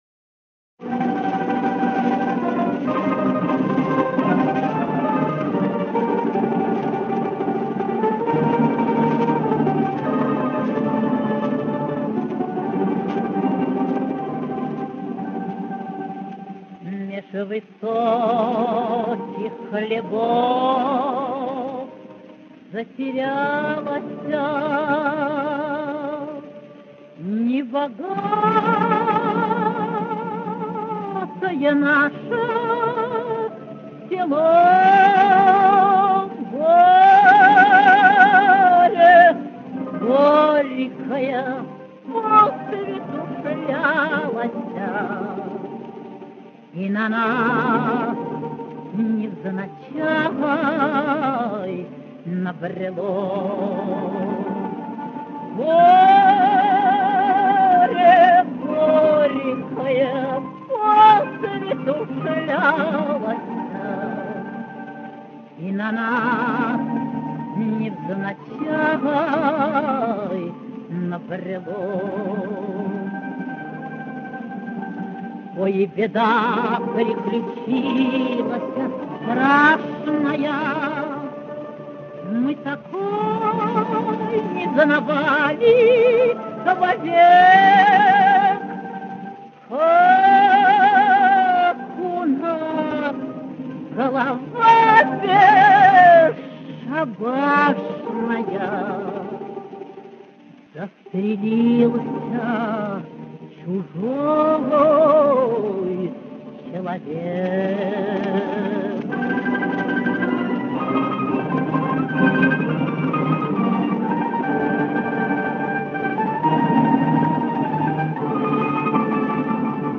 К сожалению, не очень хорошее качество.